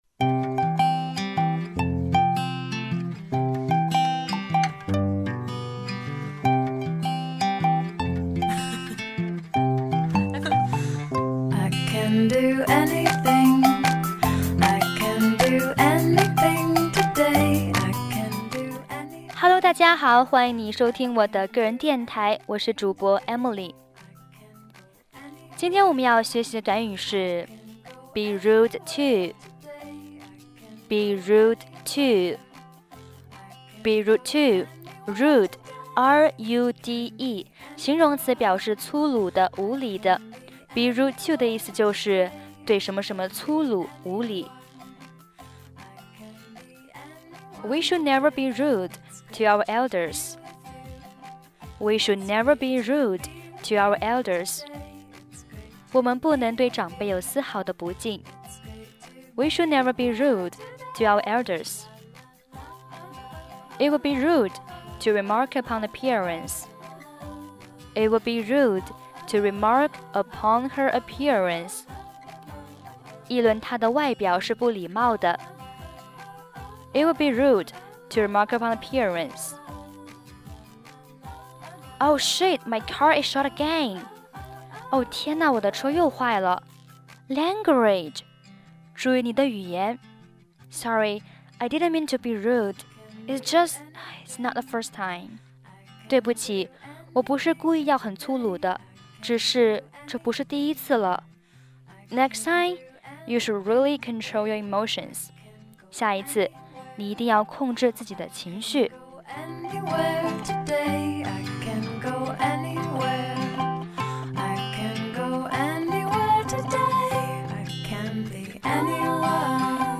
背景音乐：I can